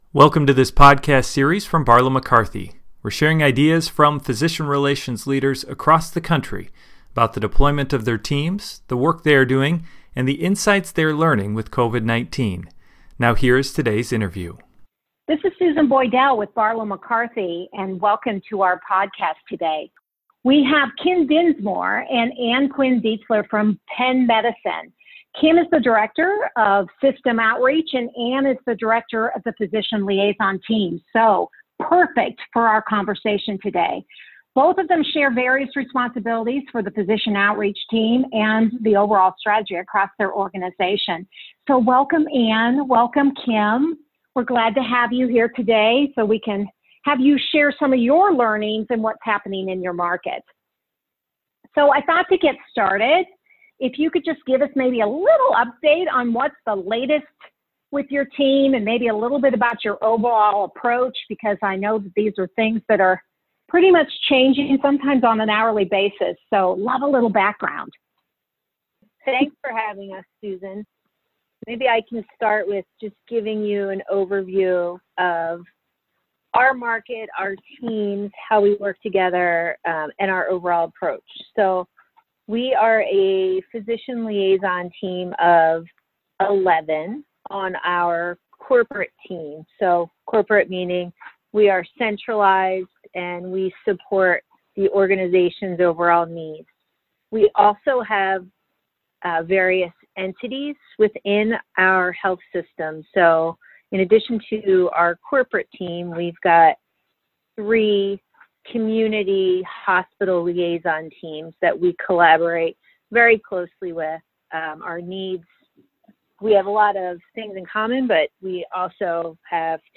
B/Mc Podcast: COVID-19 and Physician Relations – Leadership Interview #6